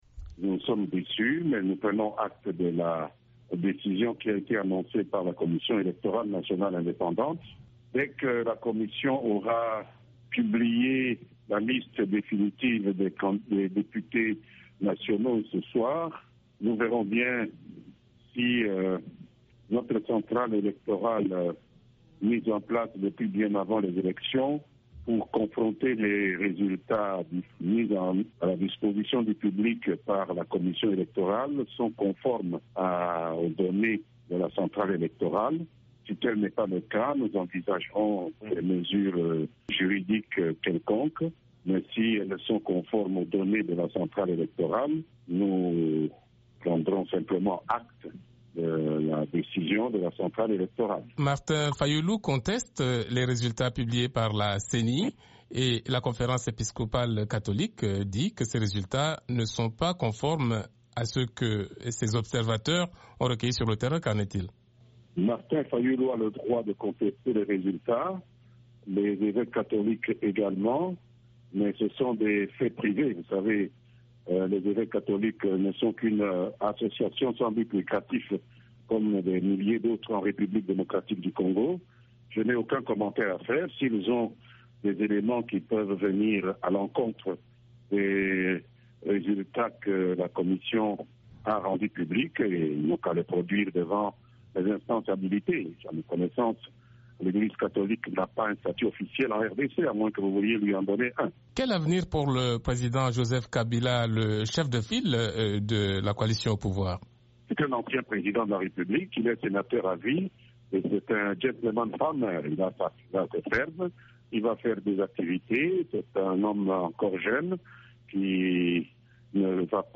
Toujours en réaction, Lambert Mende, porte-parole du gouvernement